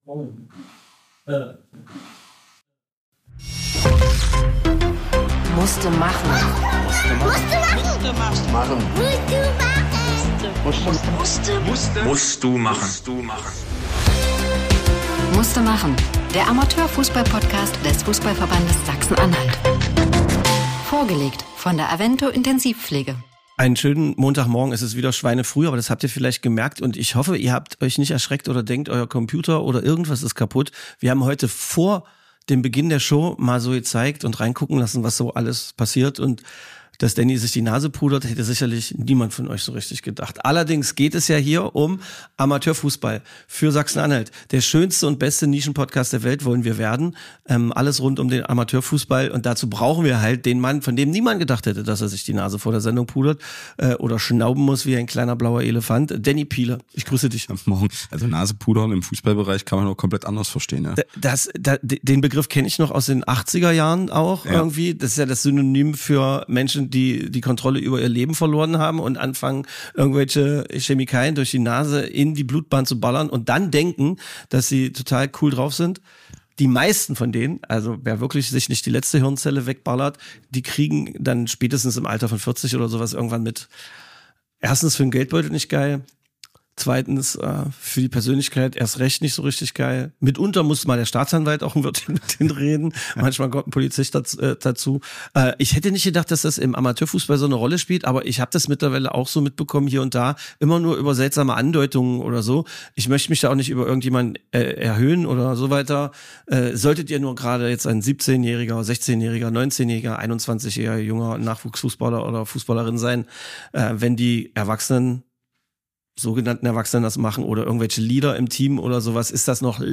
lachen viel und aus Gründen und und und...eine Folge so voll, wie manche am Wochenende.